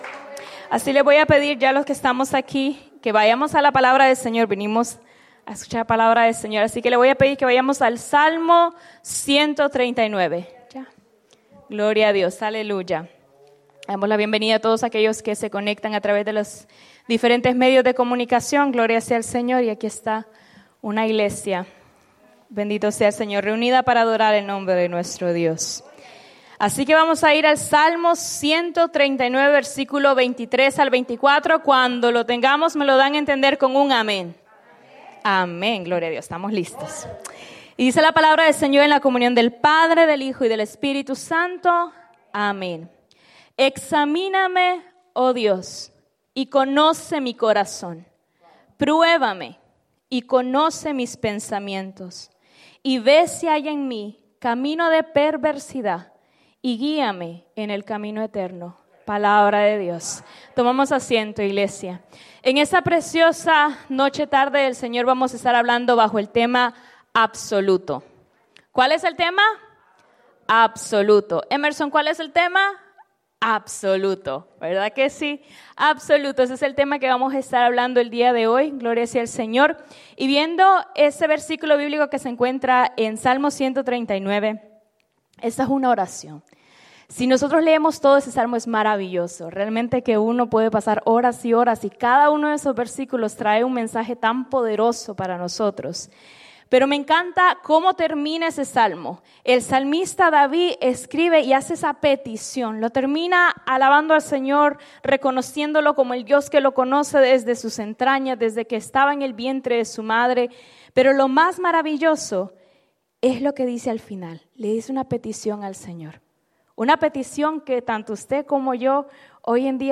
Predica
@ Souderton, PA